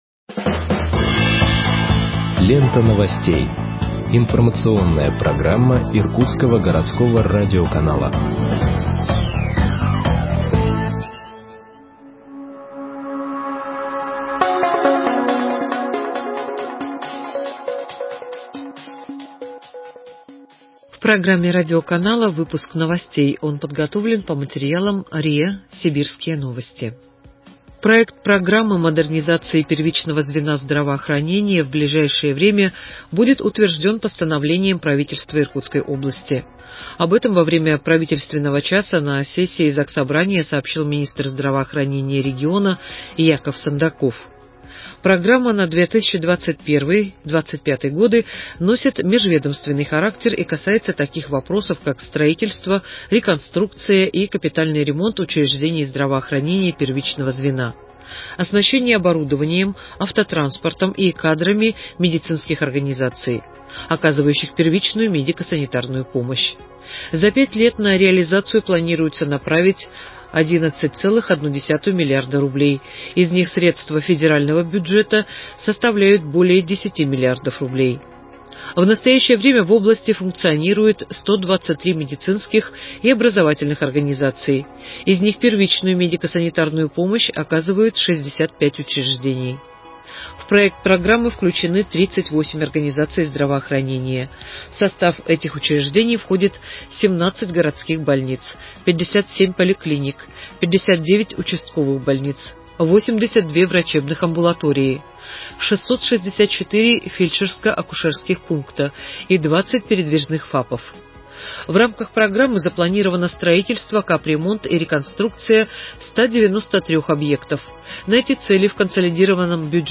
Выпуск новостей в подкастах газеты Иркутск от 17.12.2020 № 2